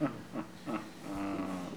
Les sons ont été découpés en morceaux exploitables. 2017-04-10 17:58:57 +02:00 306 KiB Raw Permalink History
rire_06.wav